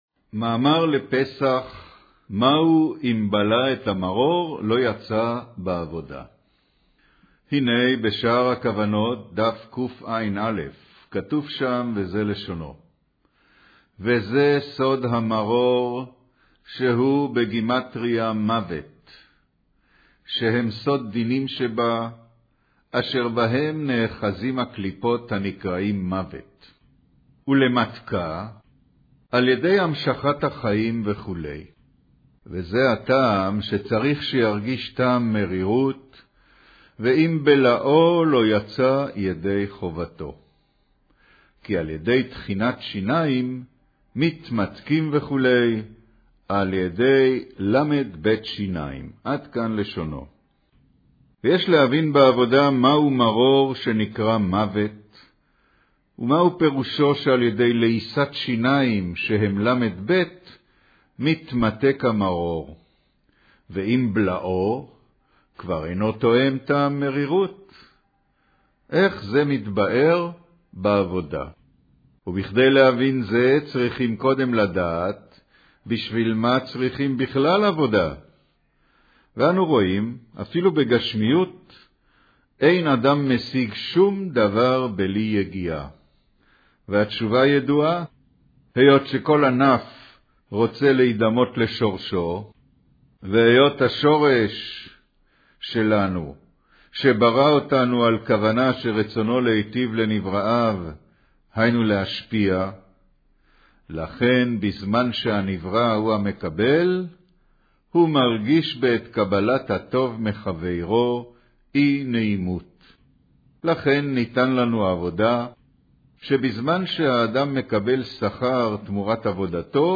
קריינות מאמר מהו אם בלעו את המרור לא יצא, בעבודה